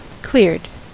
Ääntäminen
Ääntäminen US Haettu sana löytyi näillä lähdekielillä: englanti Käännös Adjektiivit 1. sdoganato {m} Cleared on sanan clear partisiipin perfekti.